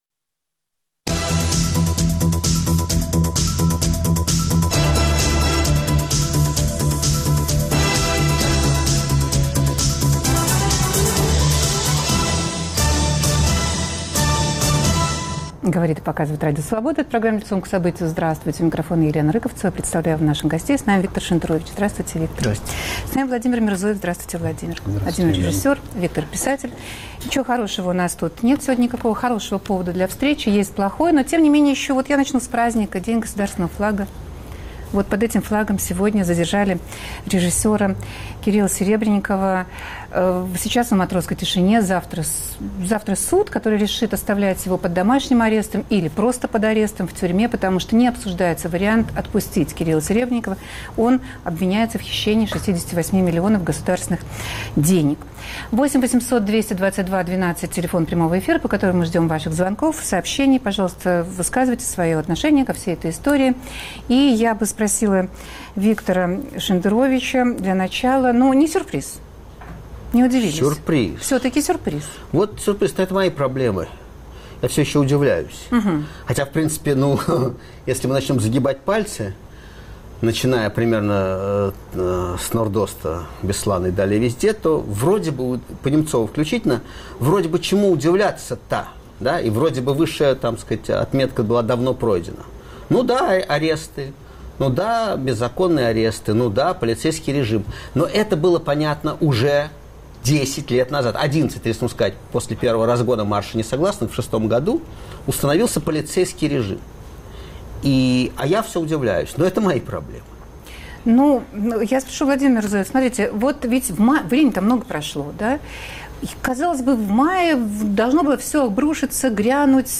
В эфире писатель Виктор Шендерович и режиссеры Иосиф Райхельгауз и Владимир Мирзоев.